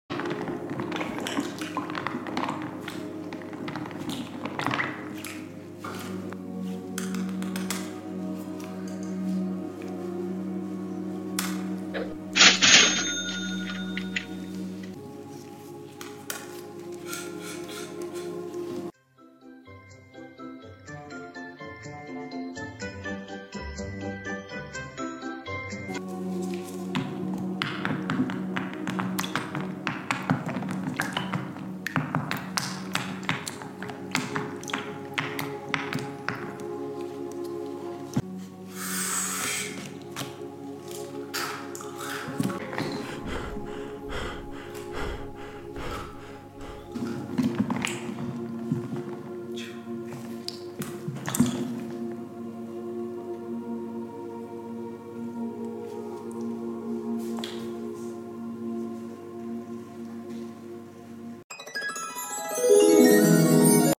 asmr from yesterday's live! balloon sound effects free download